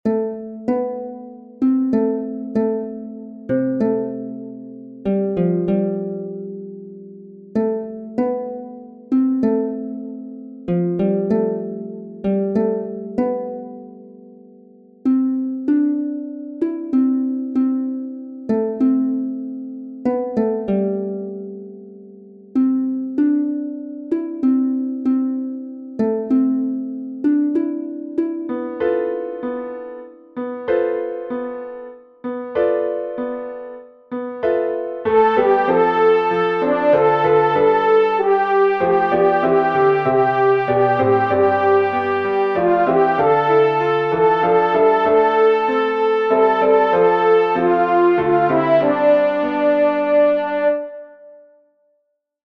Voice part practice (top of page 4 to the top of page 7):
ALTO
dream_isaiah_saw-alto.mp3